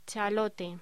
Locución: Chalota
voz